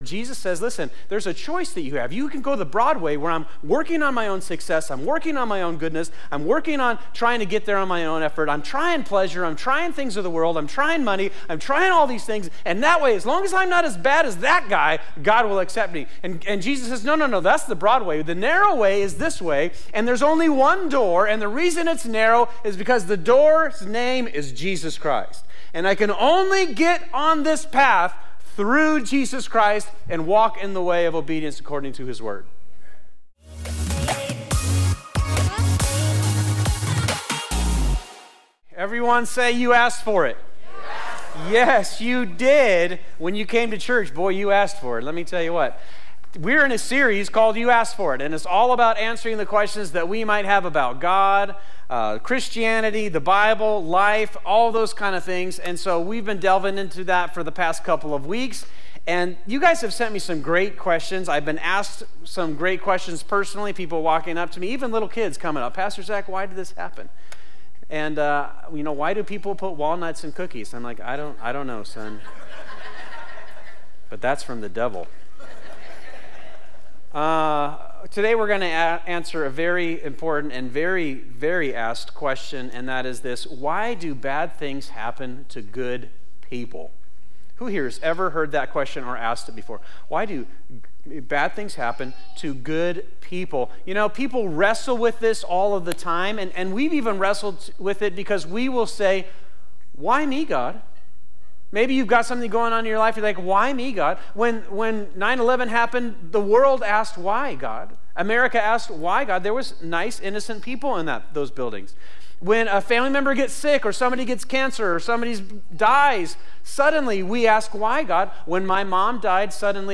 This is Part 2 of "You Asked For It," our sermon series at Fusion Christian Church where we answer real questions from members of our church community. We look to give biblical answers for the questions people have and the problems people face.